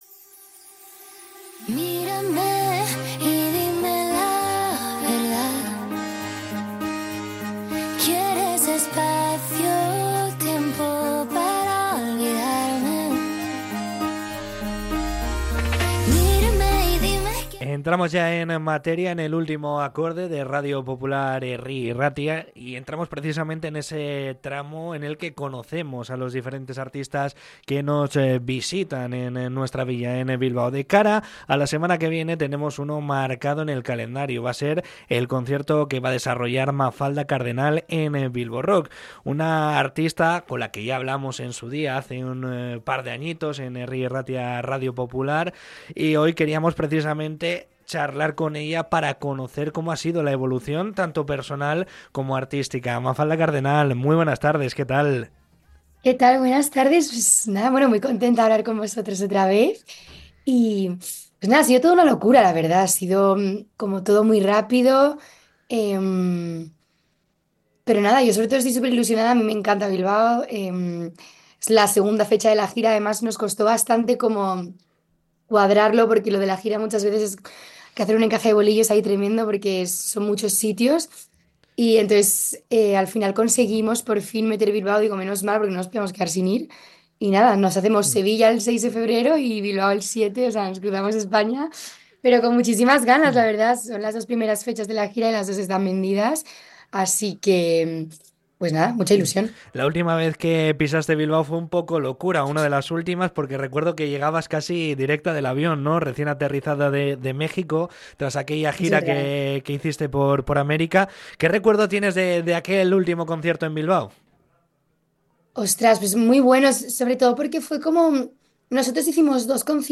Entrevista con la cantante, Mafalda Cardenal, a una semana de su concierto en Bilbao